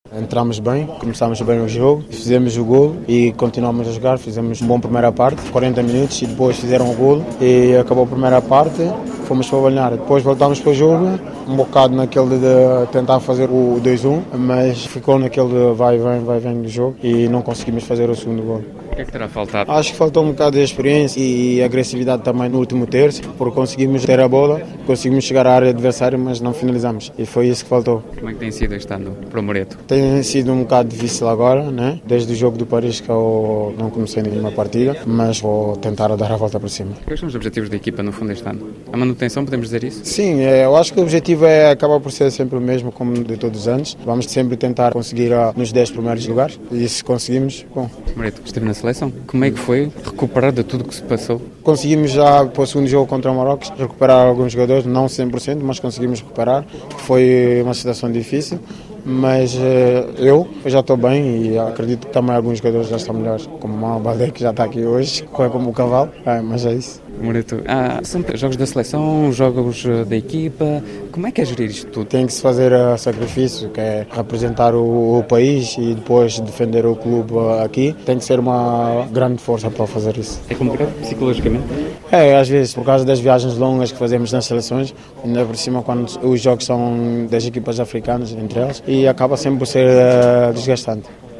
Apesar da derrota, em entrevista exclusiva à RFI, Moreto Cassamá falou do seu início de temporada que tem sido entre idas à selecção e jogos com o Reims, mas começou primeiro por abordar o jogo frente ao Troyes do amigo Mama Baldé que ele elogiou.